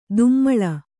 ♪ dummaḷa